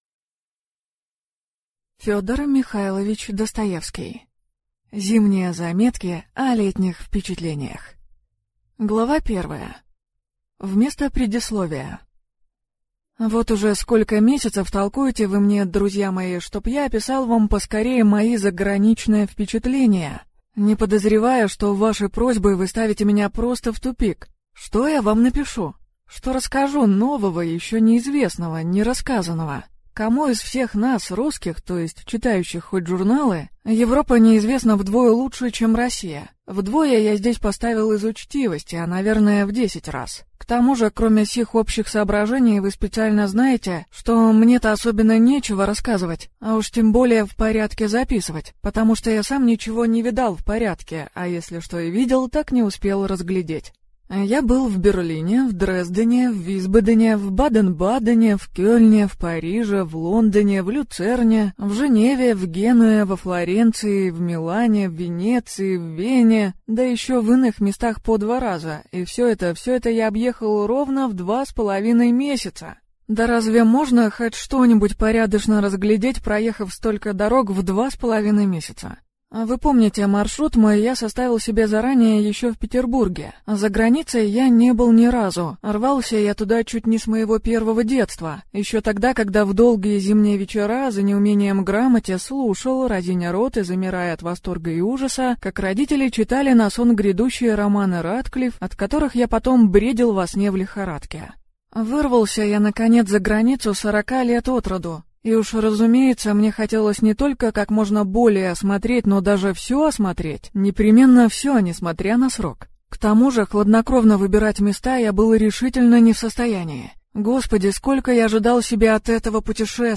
Аудиокнига Зимние заметки о летних впечатлениях | Библиотека аудиокниг